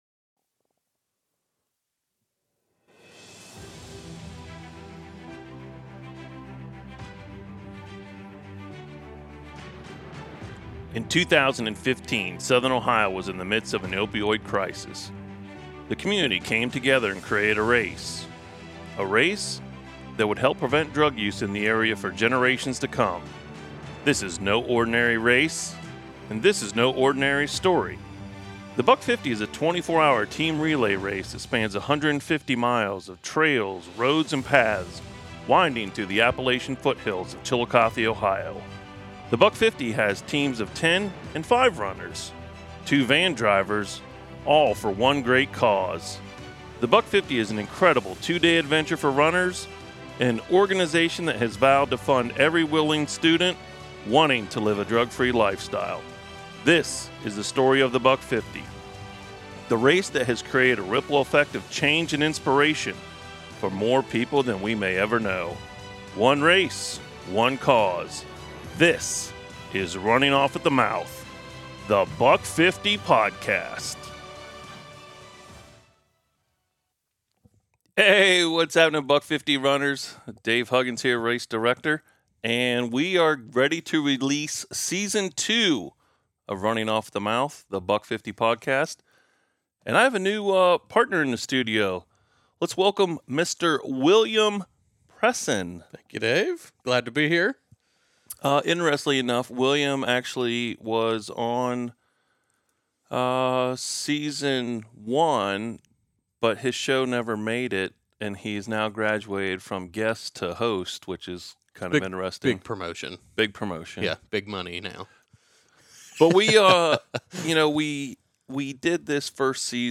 We are back in our new studio recording Running Off at The Mouth.